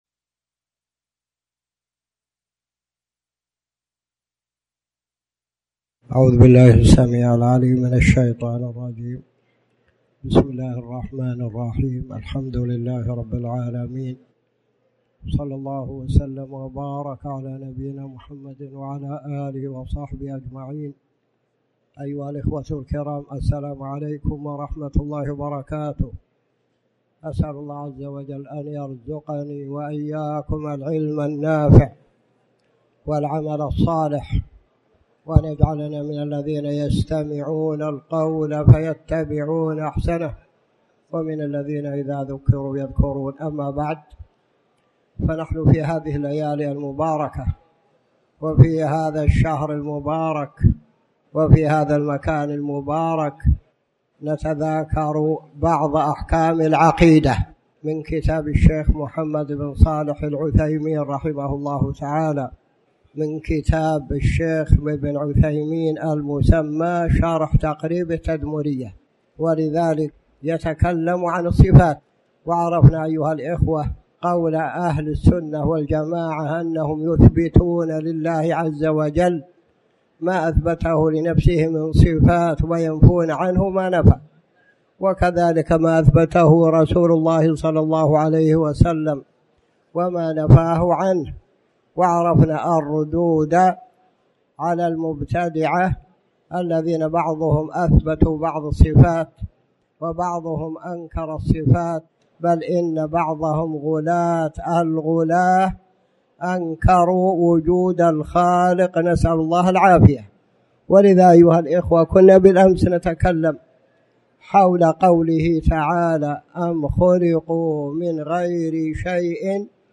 تاريخ النشر ١٧ ذو القعدة ١٤٣٩ هـ المكان: المسجد الحرام الشيخ